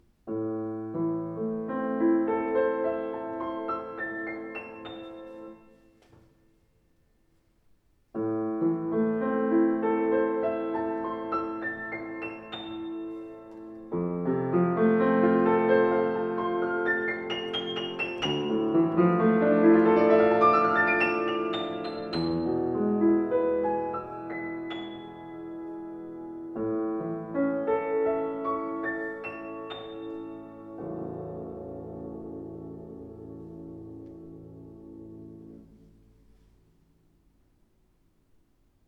Schöner, ausdruckstarker Klang, angenehme Spielart